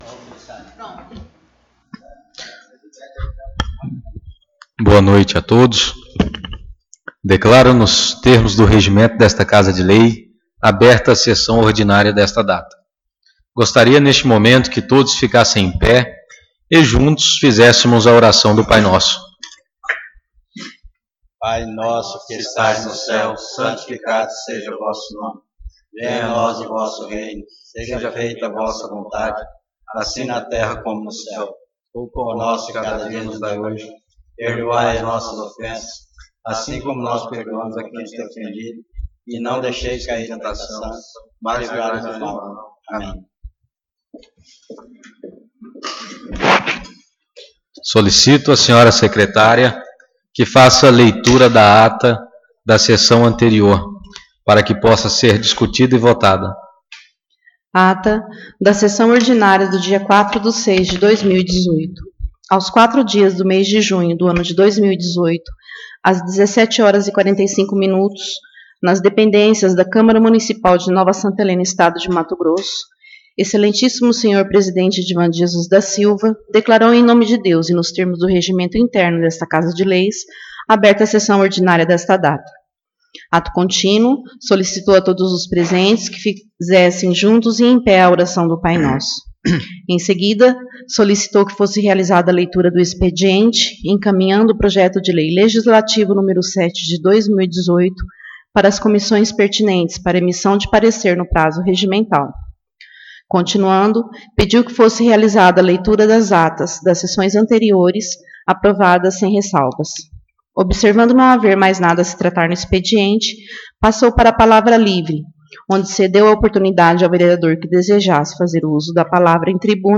Sessão Ordinária 11/06/2018